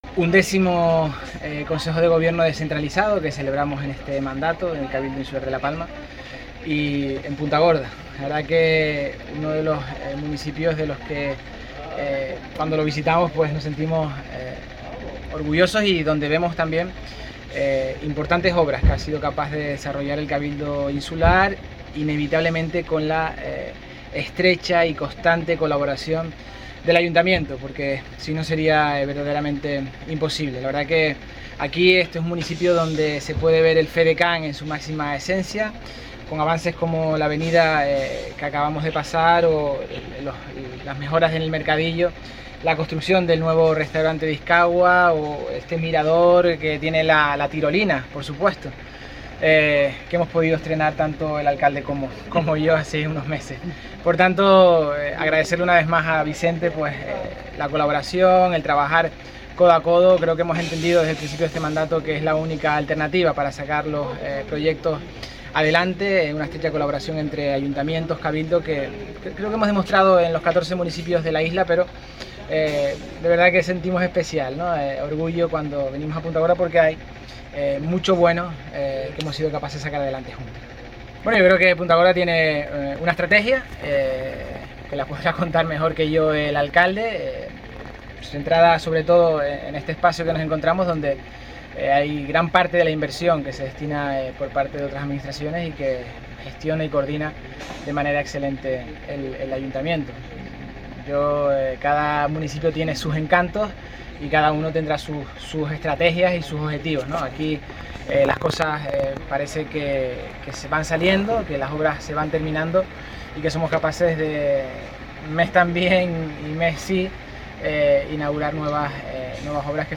Declaraciones audio Mariano Zapata.mp3